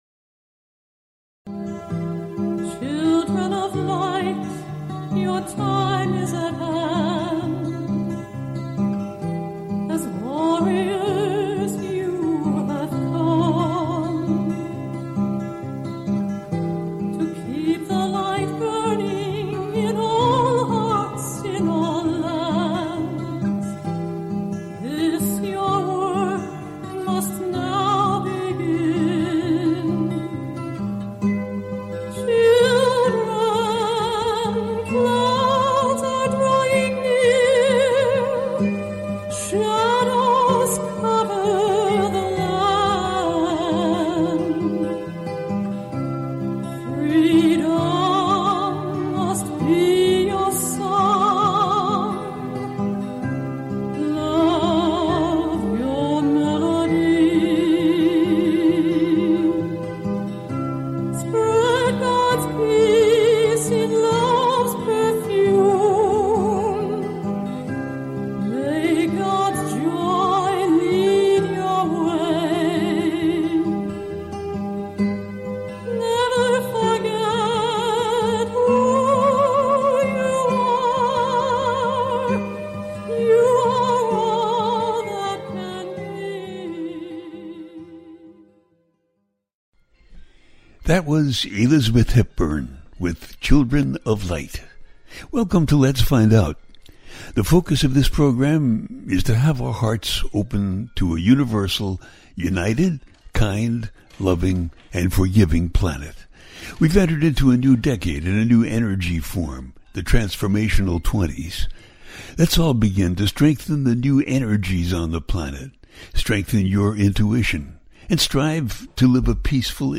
Talk Show Episode
The listener can call in to ask a question on the air.
Each show ends with a guided meditation.